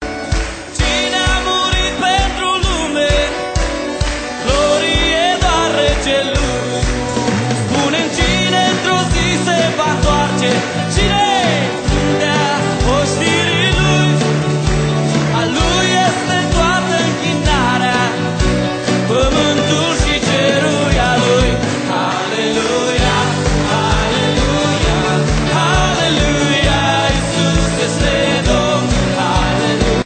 Album de lauda si inchinare inregistrat live